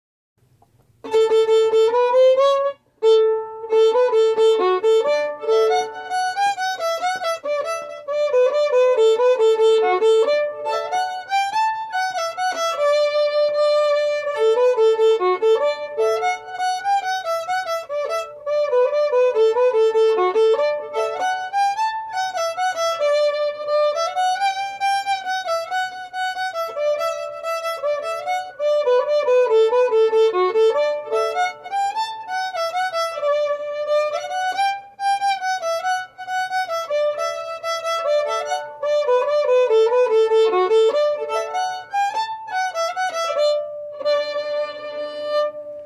Key: D
Form: Jig
Played slowly for learning
M: 6/8
Genre/Style: Scottish Borders